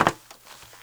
METAL 2D.WAV